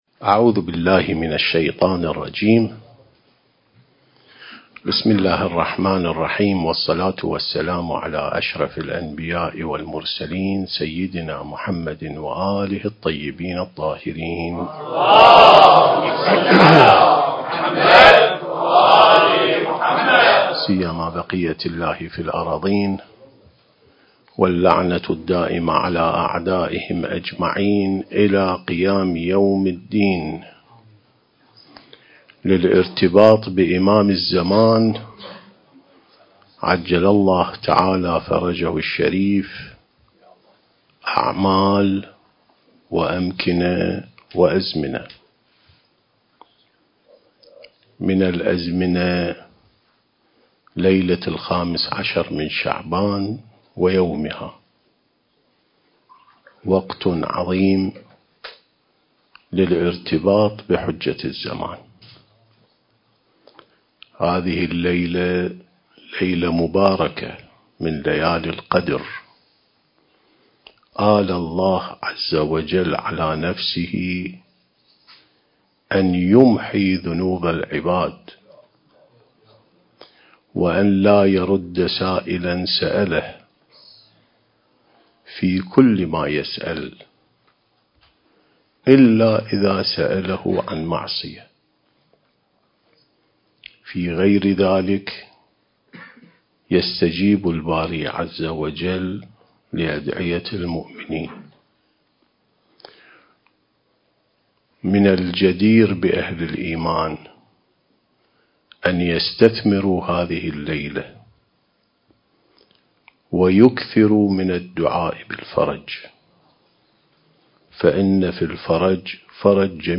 عين السماء ونهج الأنبياء سلسلة محاضرات: الارتباط بالإمام المهدي (عجّل الله فرجه)/ (6)